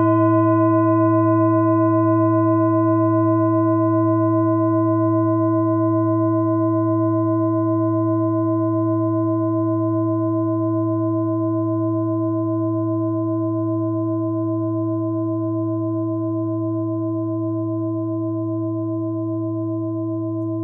Klangschalen-Typ: Bengalen
Klangschale 2 im Set 12
Klangschale N°2
(Aufgenommen mit dem Filzklöppel)
klangschale-set-12-2.wav